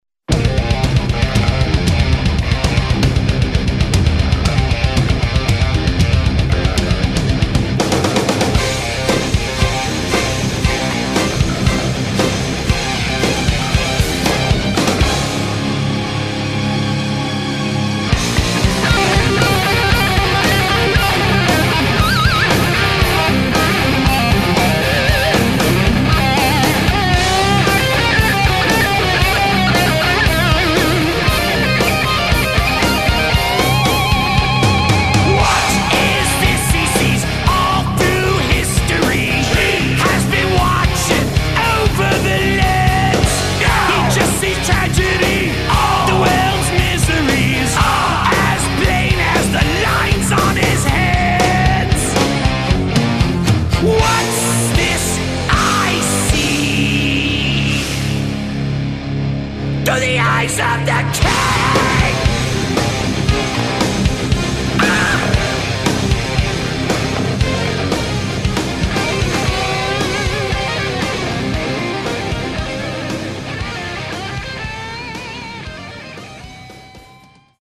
Impetuoso, coinvolgente, puramente maestoso